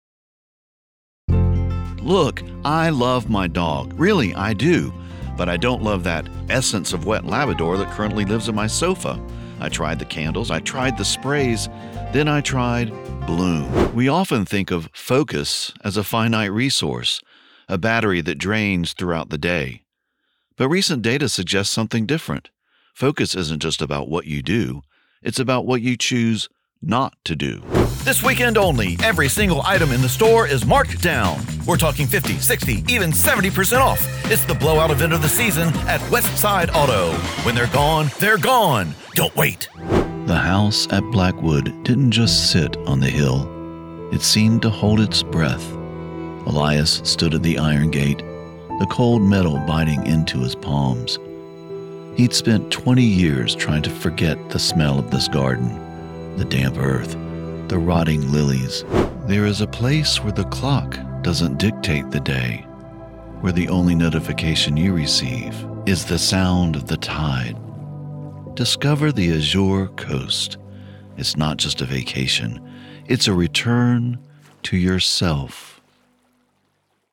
English - Midwestern U.S. English
Middle Aged